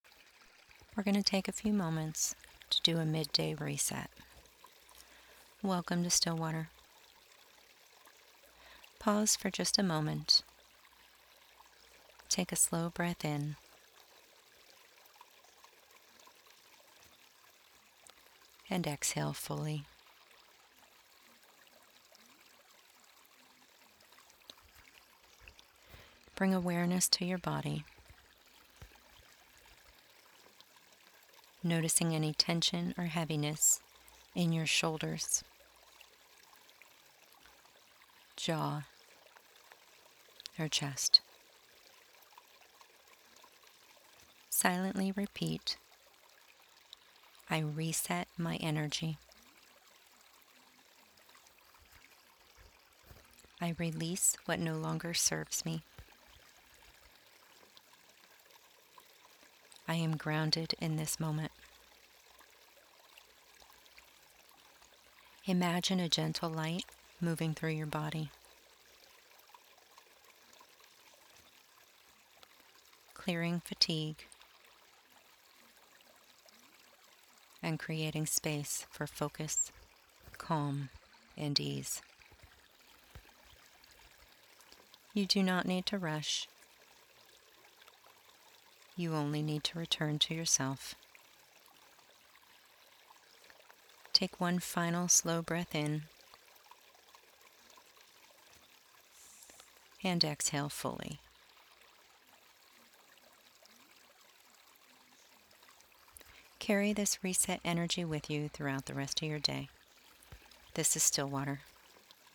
Here, you’ll find free affirmations and short guided meditations designed to help you pause, regulate, and return to calm in ways that feel realistic and accessible.